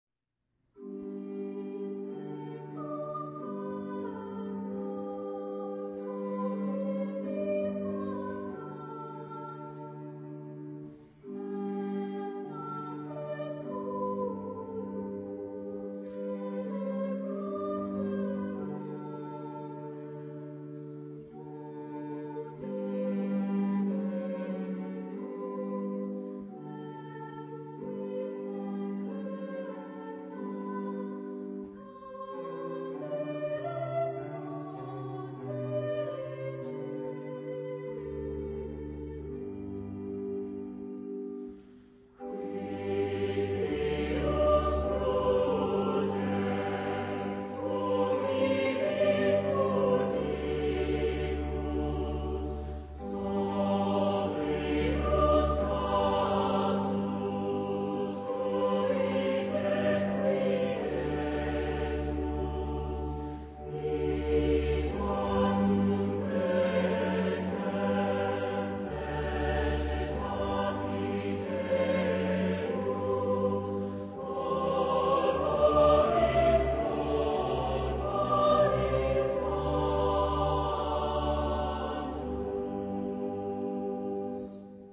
Genre-Style-Forme : Sacré ; Motet
Type de choeur : SATB  (4 voix mixtes )
Solistes : Soprano (1)  (1 soliste(s))
Instruments : Orgue (1)
Tonalité : sol majeur